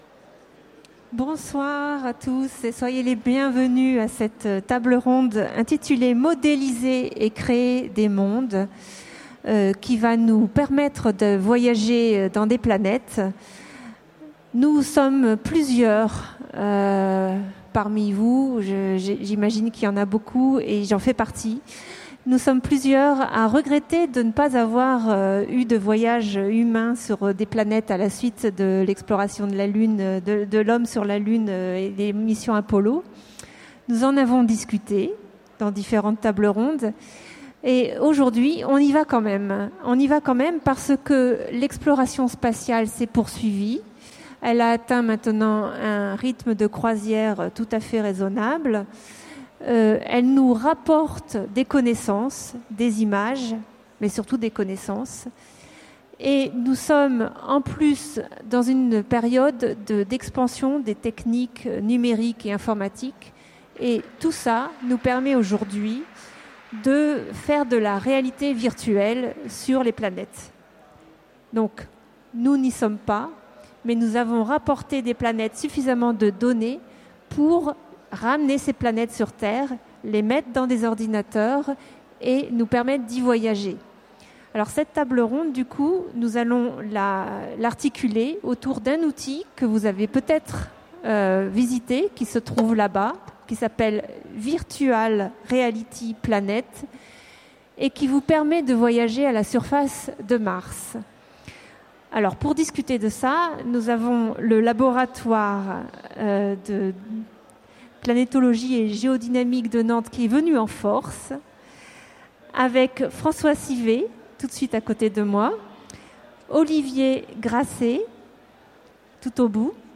Utopiales 2015 : Conférence Modéliser et créer des mondes : VR2Planets